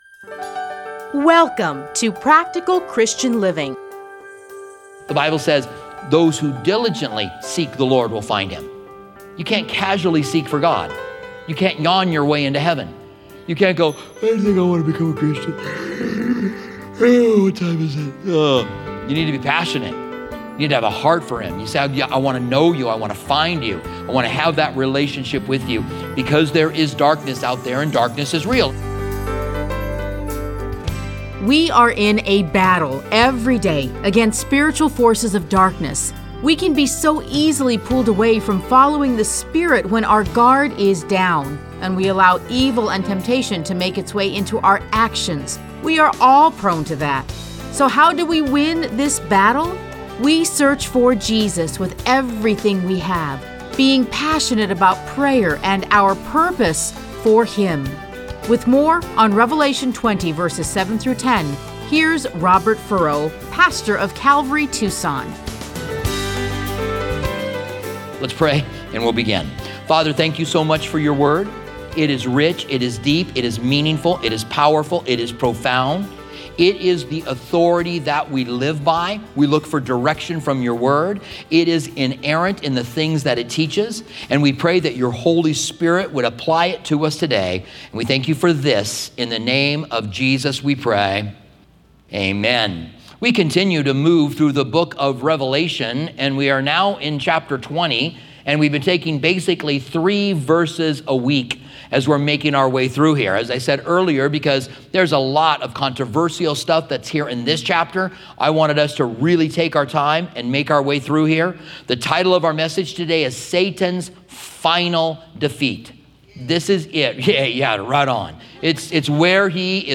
Listen to a teaching from Revelation 20:7-10.